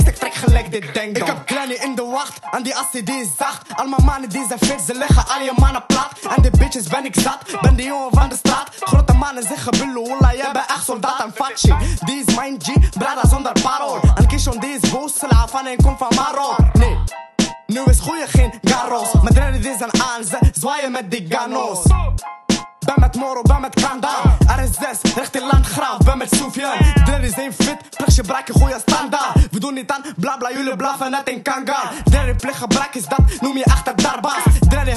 Жанр: Хип-Хоп / Рэп
Old School Rap, Hip-Hop, Rap